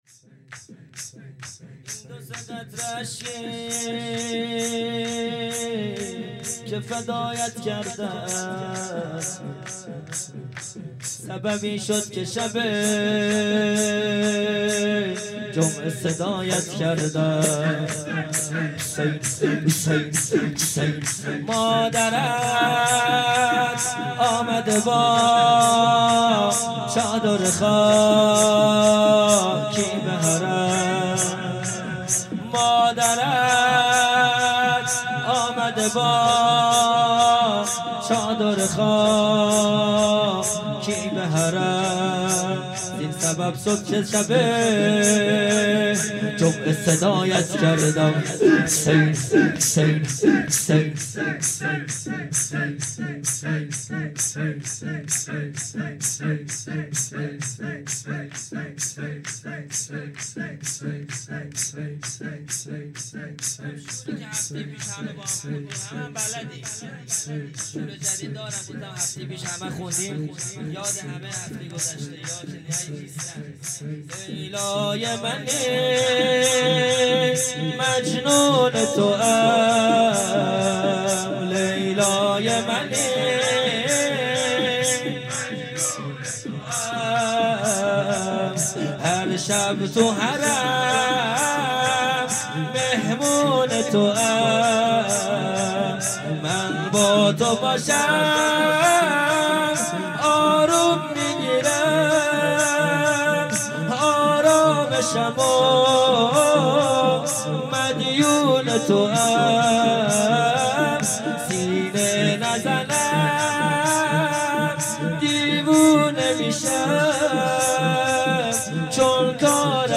خیمه گاه - هیئت بچه های فاطمه (س) - شور | میدونی یک عمر تو حسرت کرب بلا موندم
جلسه هفتگی 15 آذر 97